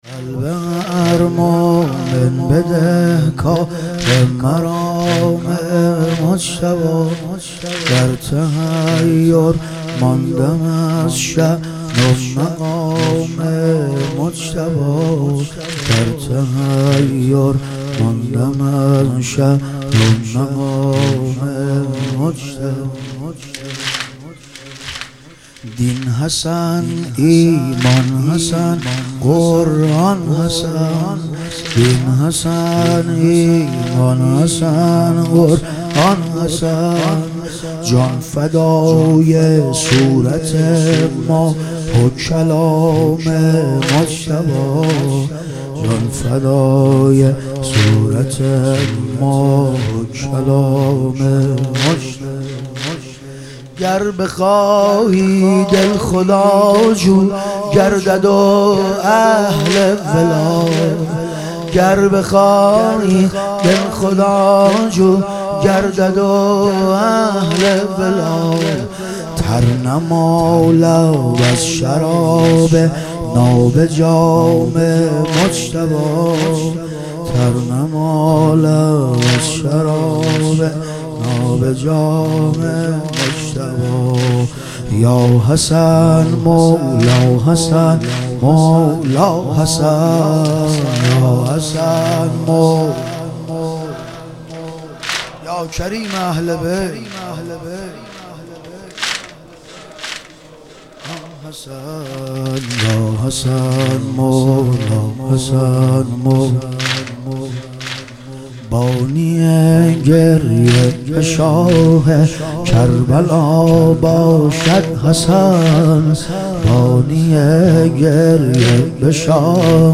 شب شهادت امام حسن مجتبی علیه السلام